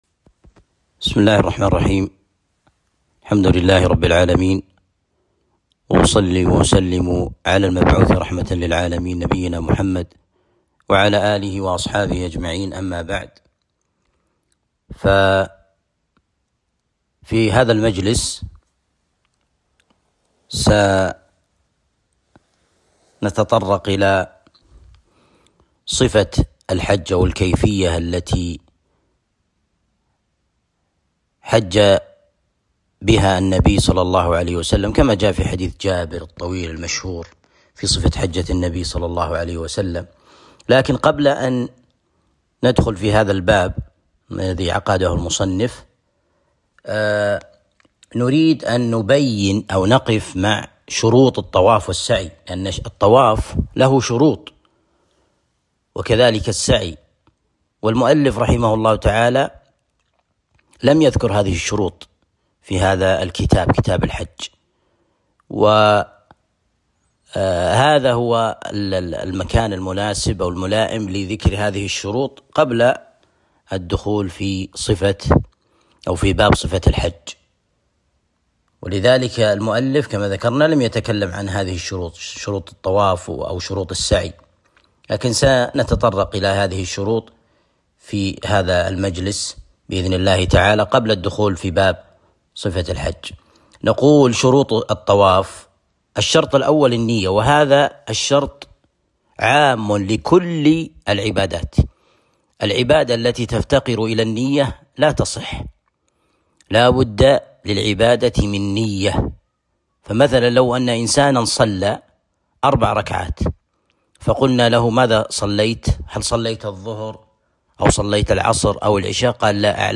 شرح كتاب الحج من عمدة الفقه - الدرس الخامس